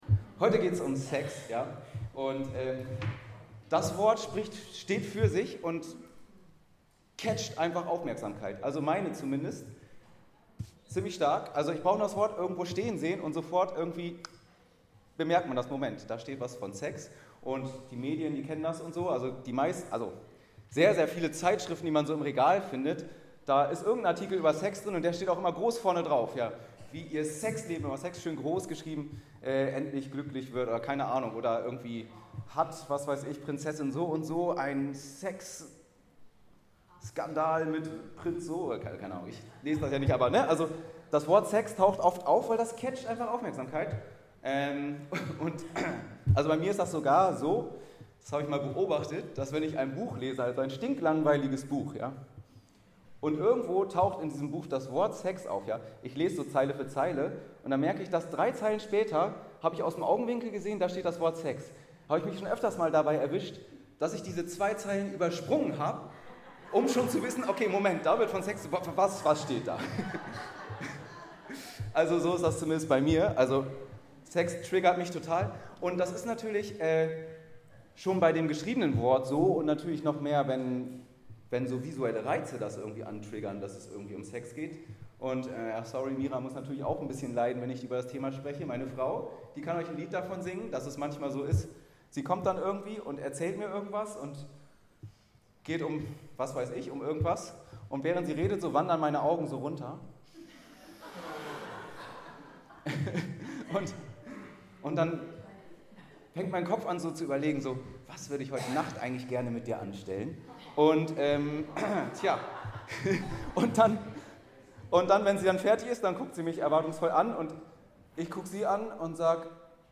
Predigtreihe Sex – Teil 1 ~ Anskar-Kirche Hamburg- Predigten Podcast